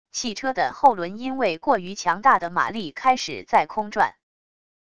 汽车的后轮因为过于强大的马力开始在空转wav音频